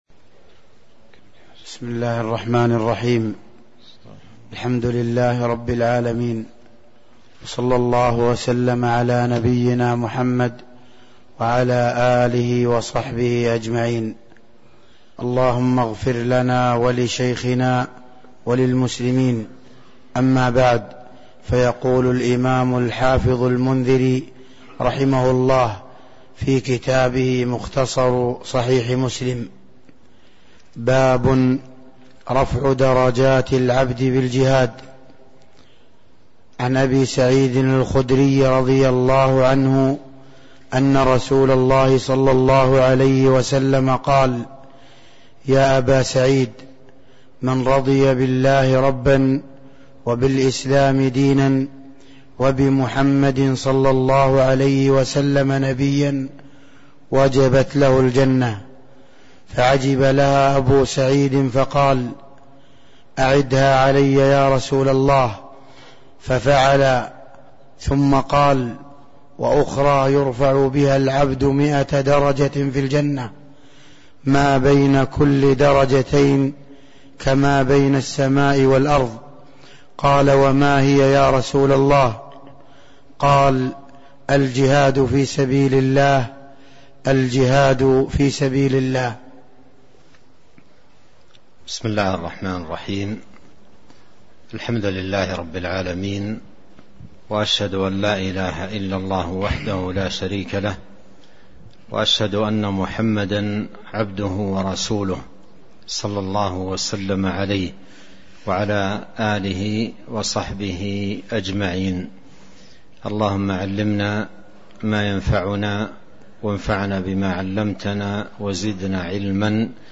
تاريخ النشر ٢١ ربيع الأول ١٤٤٣ هـ المكان: المسجد النبوي الشيخ